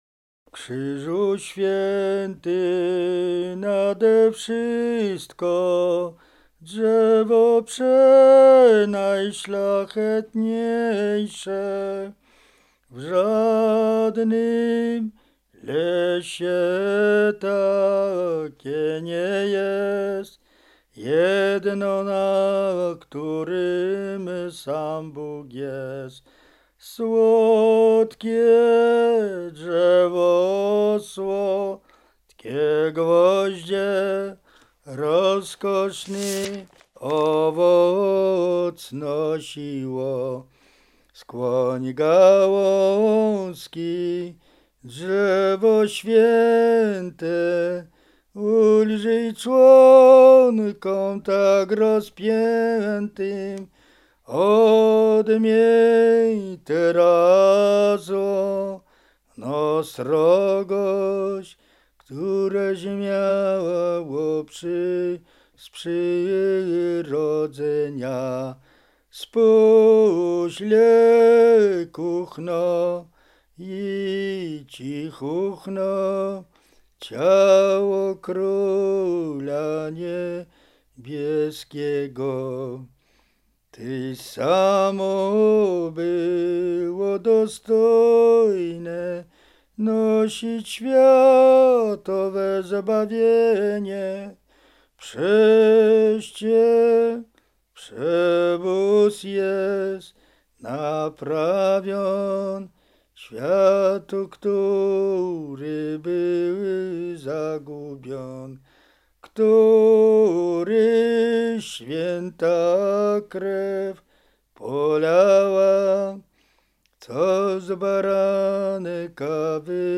Lubelszczyzna
Roztocze
Wielkopostna